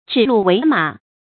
注音：ㄓㄧˇ ㄌㄨˋ ㄨㄟˊ ㄇㄚˇ
指鹿為馬的讀法